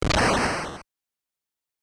Super C SFX (12).wav